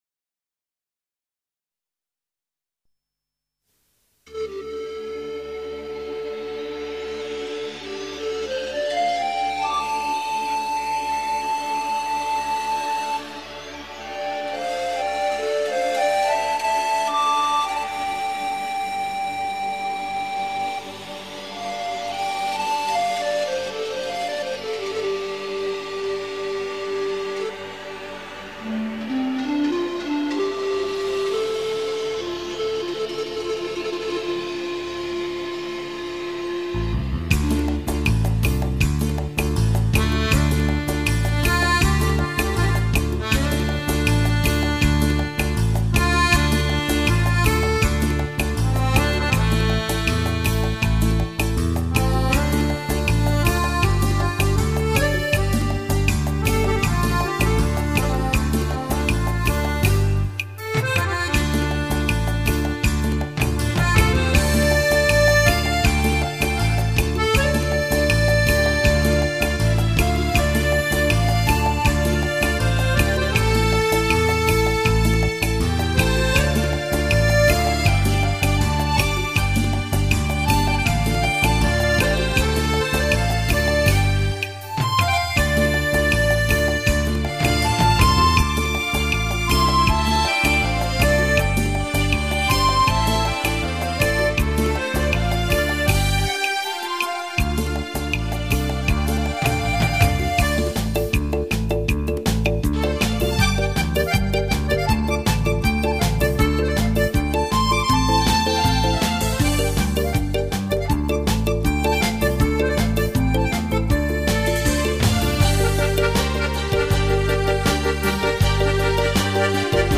技巧細緻，風格獨特，抒情雅致。
專輯收錄國內外手風琴暢銷金曲，旋律優美，曲曲動聽，提供您更